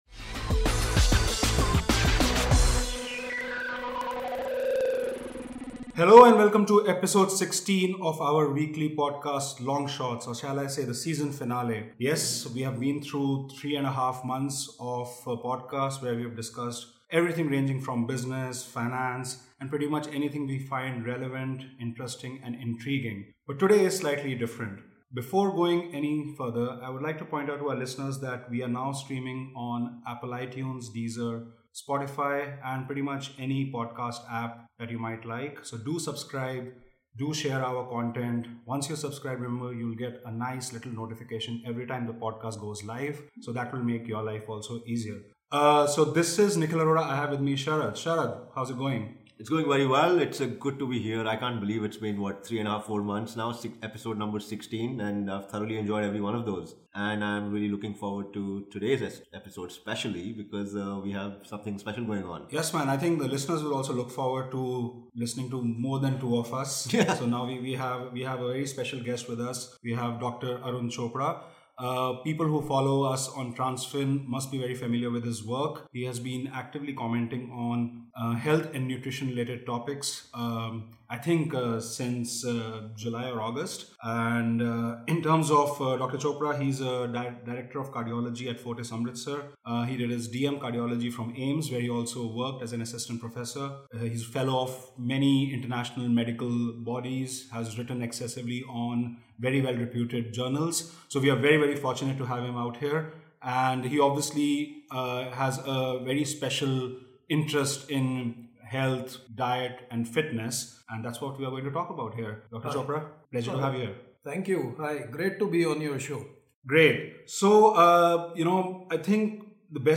In Conversation with a Doctor: Nutrition, Exercise, Health Tips, and Policy